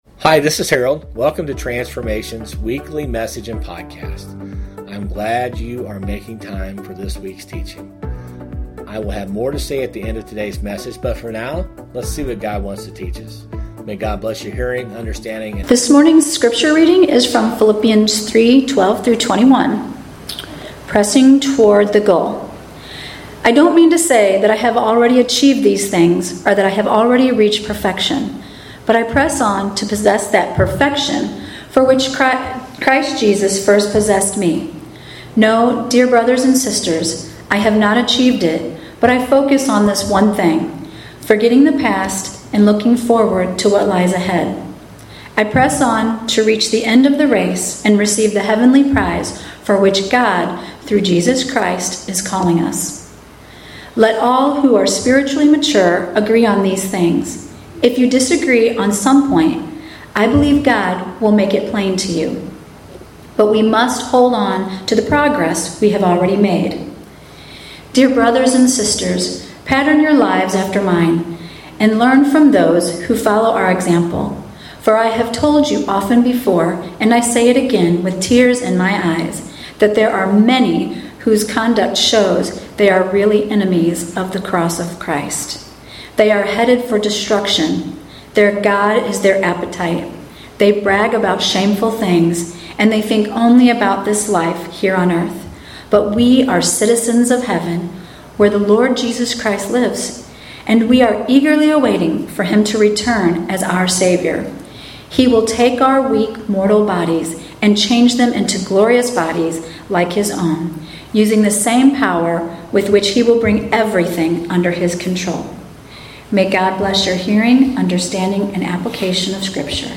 Sermons | Transformation Church